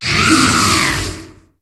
Cri de Bacabouh dans Pokémon HOME.